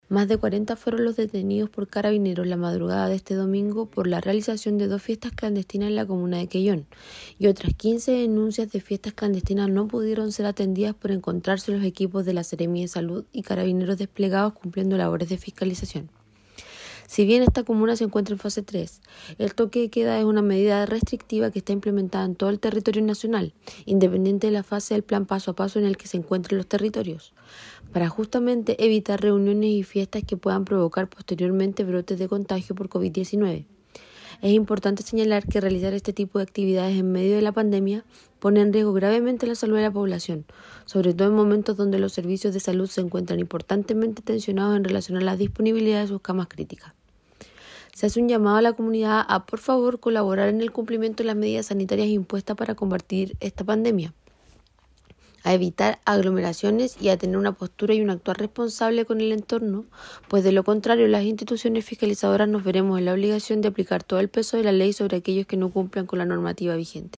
De la situación ocurrida en Quellón, también dio cuenta la jefa la Autoridad Sanitaria, María Fernanda Matamala, quien expresó que alrededor de 15 denuncias más de fiestas se produjeron en Quellón, sin embargo, no pudieron ser atendidas ya que los equipos concurrieron primero a estas dos vulneraciones a la emergencia sanitaria.